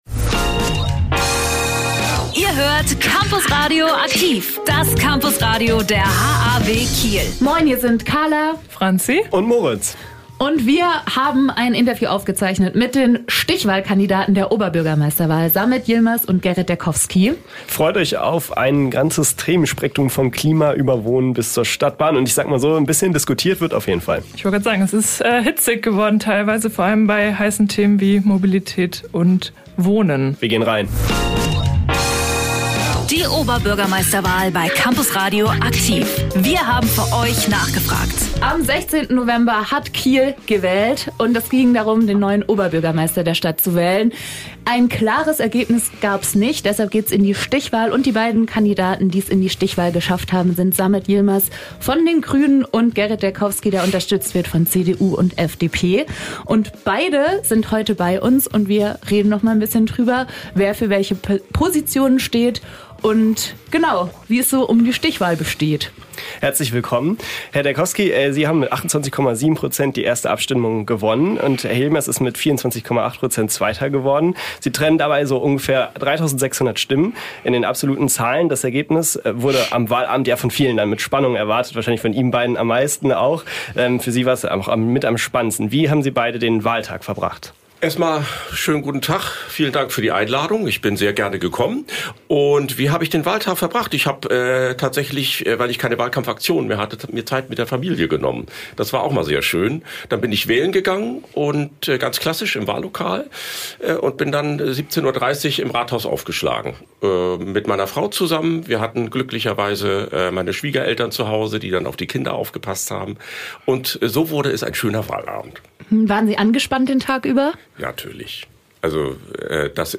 Beide haben erneut den Weg zu uns ins Studio gefunden, diesmal gemeinsam.